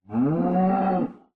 cow
should be correct audio levels.